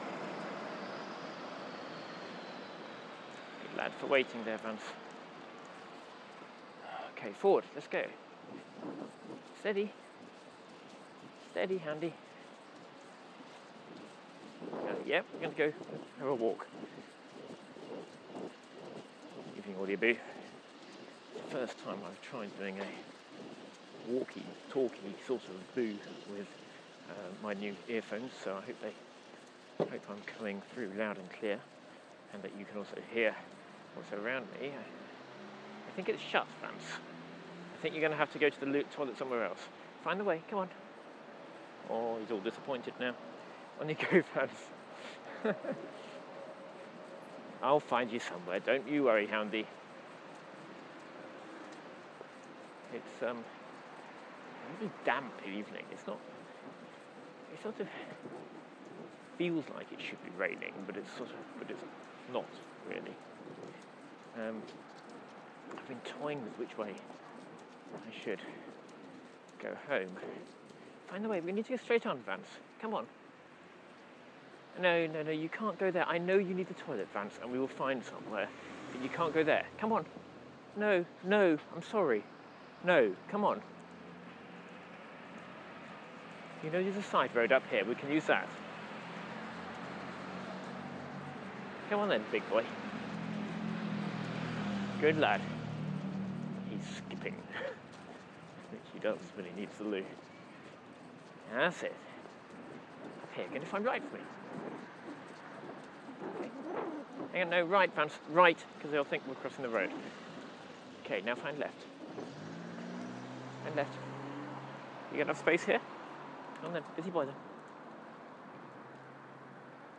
A rather damp walk to Waterloo via the Palace of Westminster, Whitehall, Charing Cross and the Jubilee Footbridge